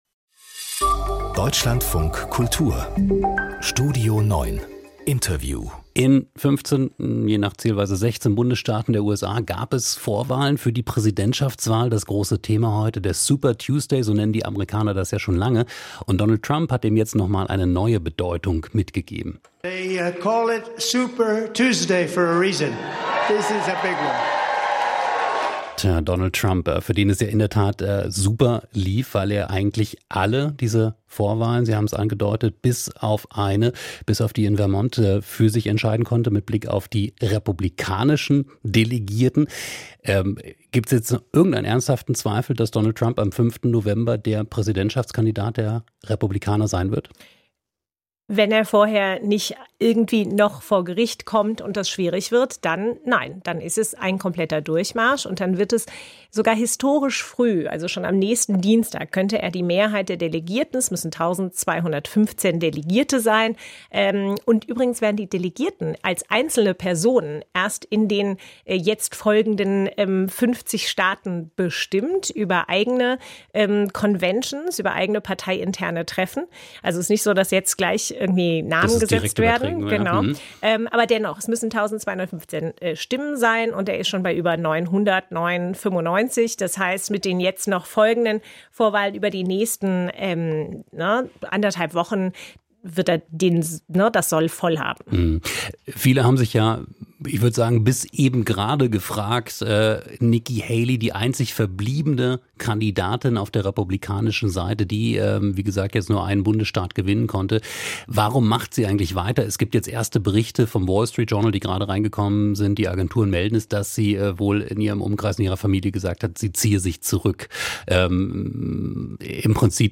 Die Themen des Tages mit einem Gast aus Medien, Kultur oder Politik: Das ist die Mischung von...